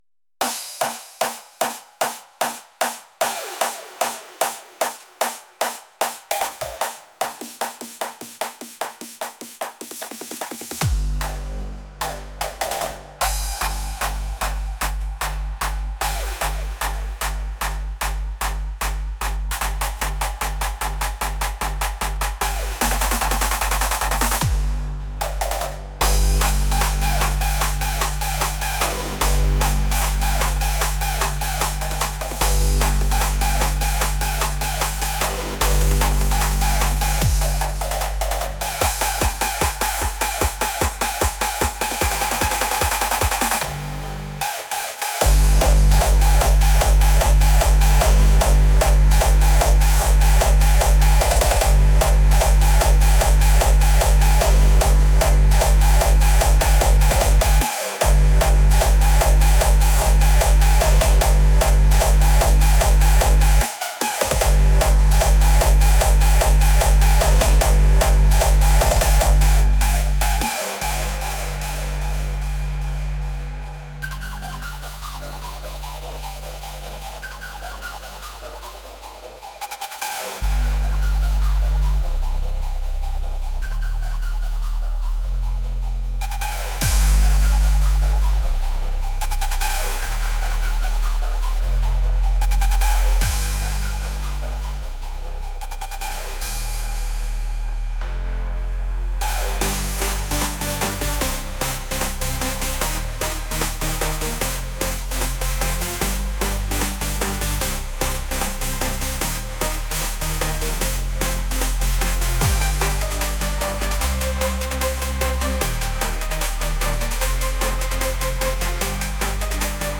intense | energetic